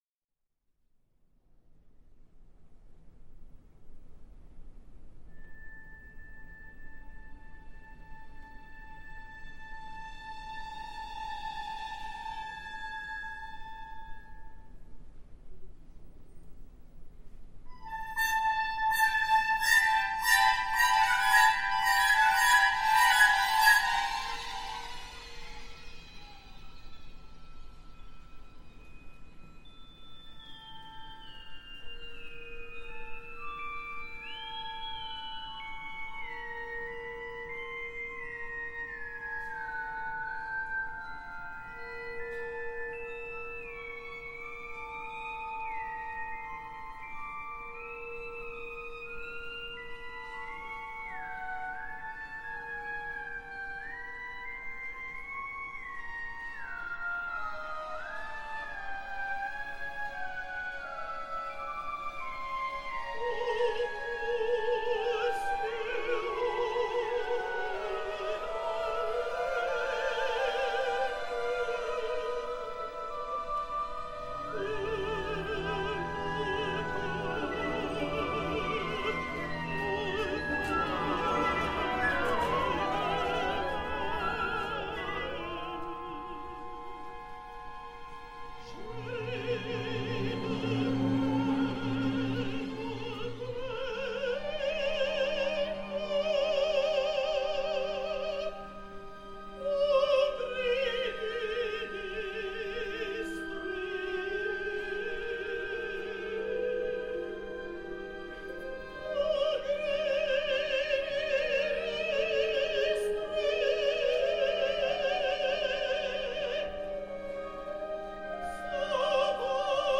Его напевает призрак придворной дамы.